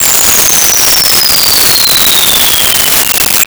Bomb Fall 02
Bomb Fall 02.wav